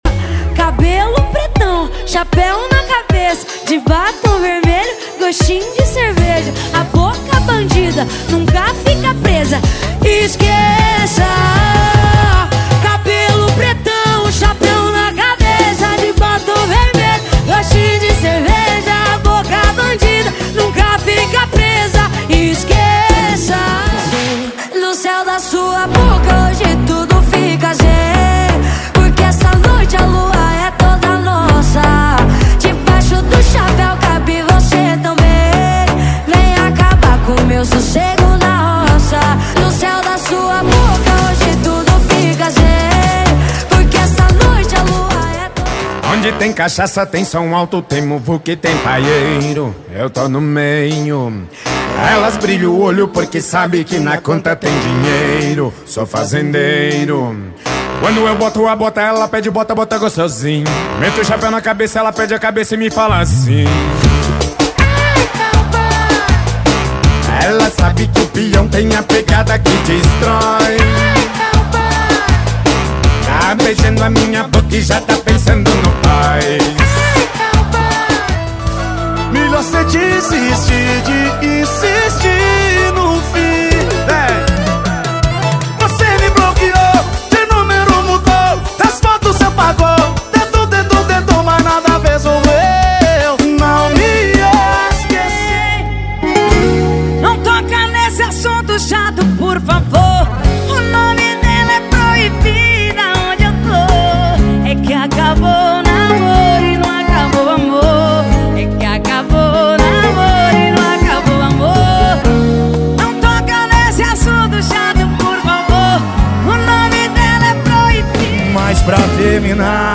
• Em Alta Qualidade